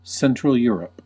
Ääntäminen
Ääntäminen US RP : IPA : /ˈsɛntrəl ˈjʊəɹəp/ US : IPA : /ˈsɛntrəl ˈjʊɹəp/ Haettu sana löytyi näillä lähdekielillä: englanti Käännös Ääninäyte Erisnimet 1.